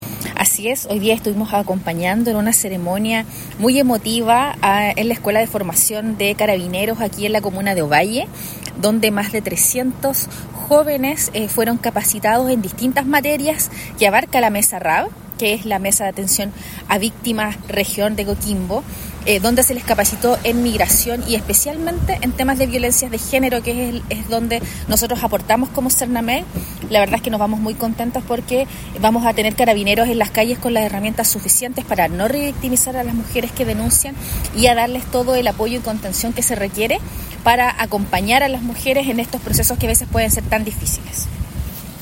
Al respecto, Priscilla Olivares, directora regional del SernamEG reforzó,
Priscilla-Olivares-Verasay-directora-regional-del-SernamEG.mp3